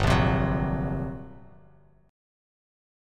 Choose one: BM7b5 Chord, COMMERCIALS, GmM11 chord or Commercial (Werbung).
GmM11 chord